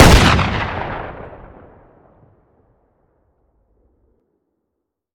weap_western_fire_plr_atmo_ext1_03.ogg